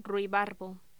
Locución: Ruibarbo
voz
Sonidos: Voz humana